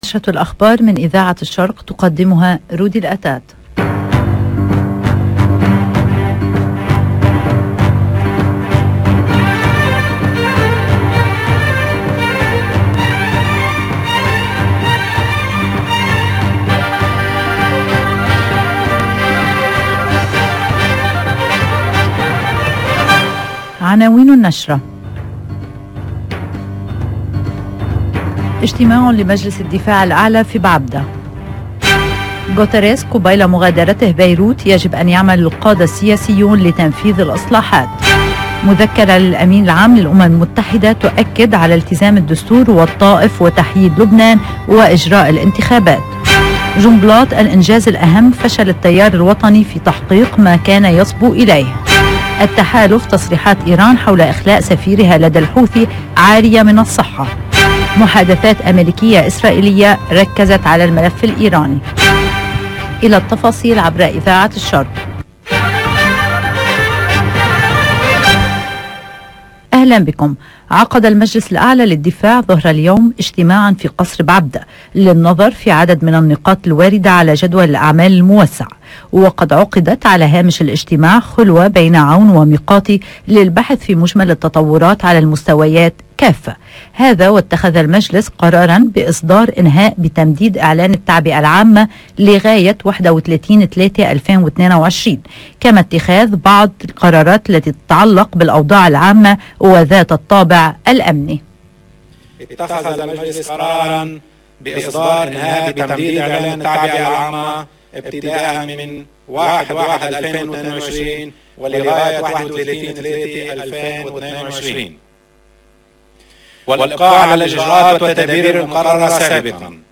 LE JOURNAL DE 13H30 DU LIBAN EN LANGUE ARABE DU 22/12/2021